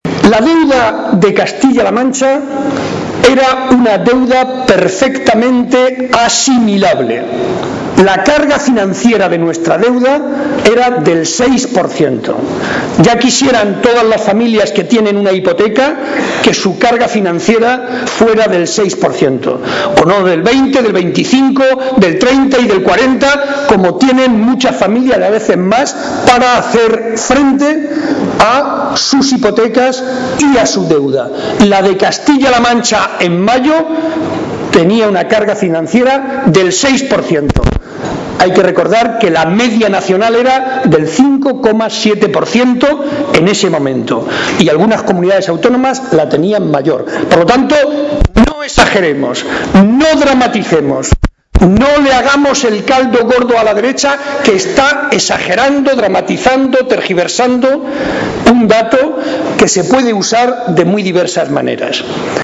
Barreda hacía estas declaraciones en el transcurso del Comité Provincial Extraordinario del PSOE de Ciudad Real donde ha sido ratificado como candidato número 1 al Congreso de los Diputados, un foro en el que ha denunciado la “campaña bestial” a la que está siendo sometido por parte de Cospedal y el resto de dirigentes del PP a base de “infamias, graves insultos y calumnias”.
Cortes de audio de la rueda de prensa